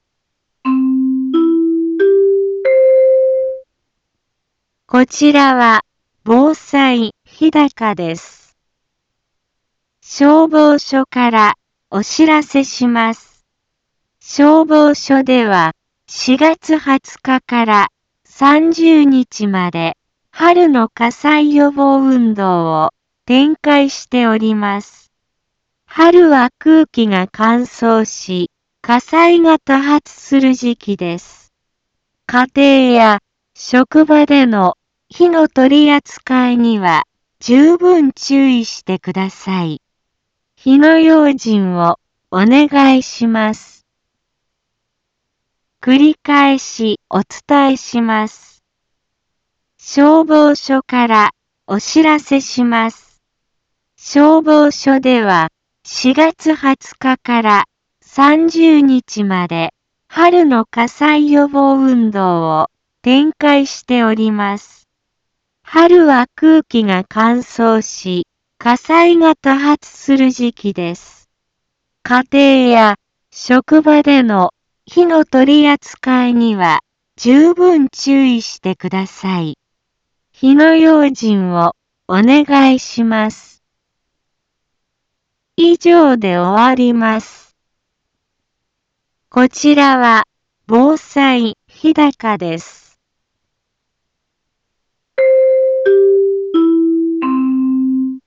一般放送情報
Back Home 一般放送情報 音声放送 再生 一般放送情報 登録日時：2023-04-20 10:03:27 タイトル：春の火災予防運動について インフォメーション：こちらは防災日高です。